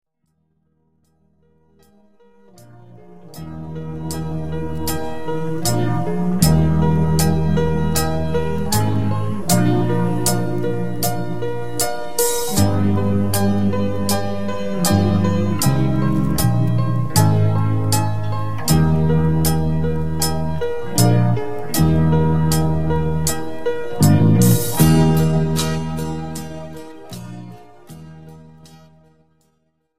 This is an instrumental backing track cover.
• Key – G
• Without Backing Vocals
• No Fade